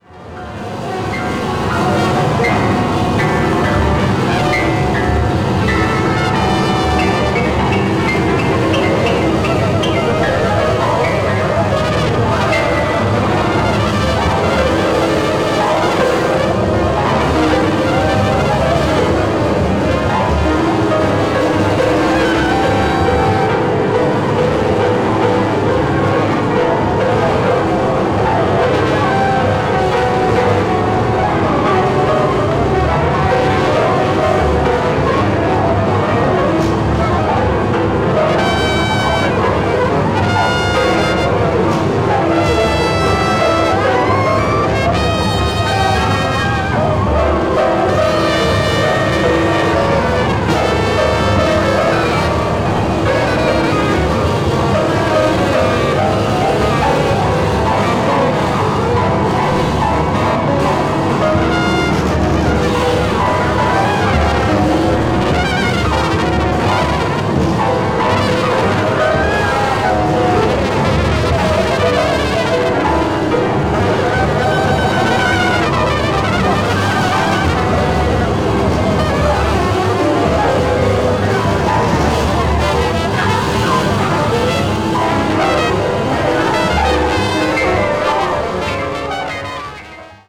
avant-jazz   free improvisation   free jazz   spiritual jazz